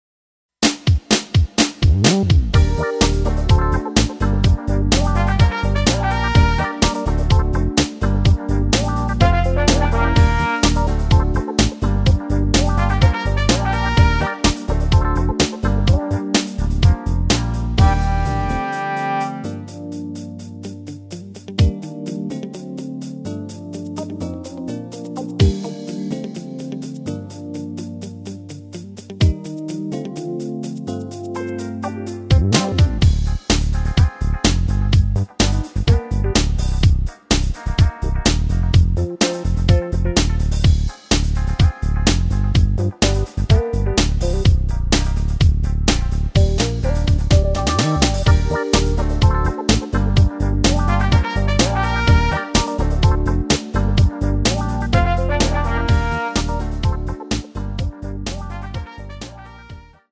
Gm
앞부분30초, 뒷부분30초씩 편집해서 올려 드리고 있습니다.
중간에 음이 끈어지고 다시 나오는 이유는